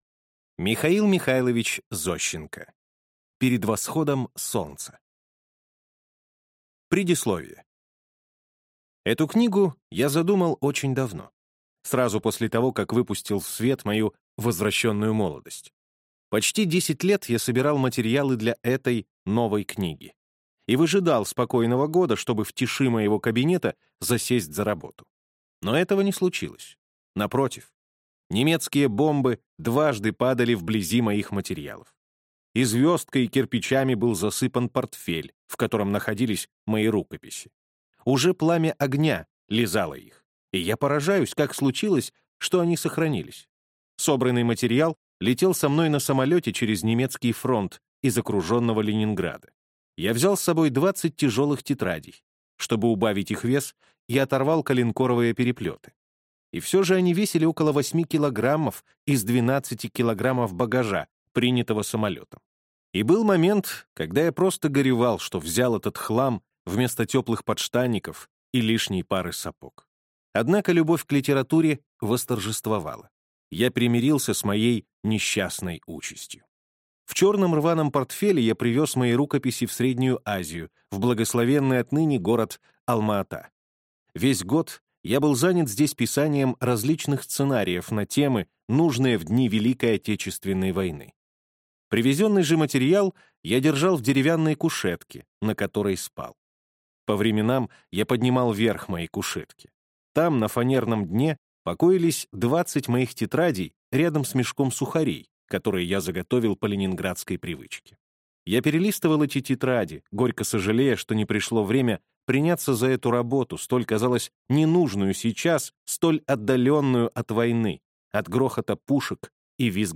Аудиокнига Перед восходом солнца | Библиотека аудиокниг